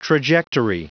Prononciation du mot trajectory en anglais (fichier audio)
Prononciation du mot : trajectory